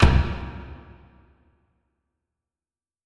Kick (Amazing).wav